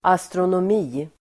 Uttal: [astronåm'i:]